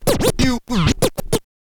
scratch_kit01_01.wav